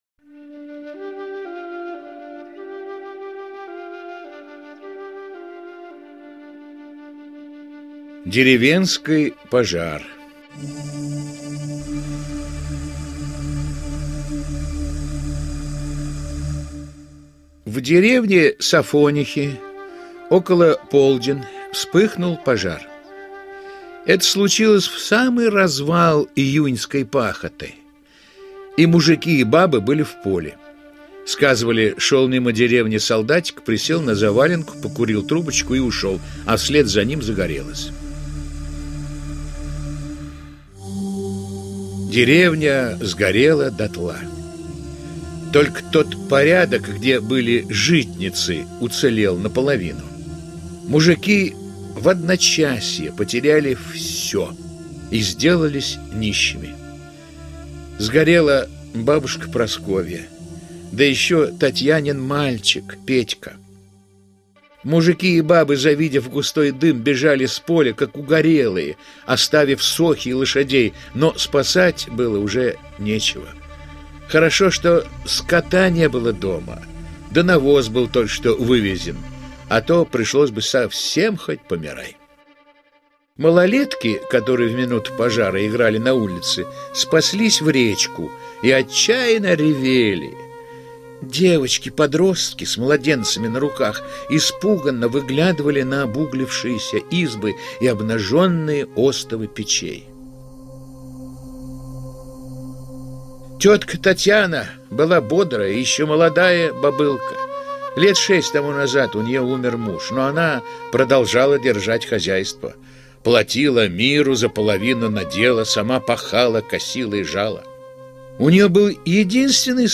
Деревенский пожар - аудиосказка Михаила Салтыкова-Щедрина - слушать онлайн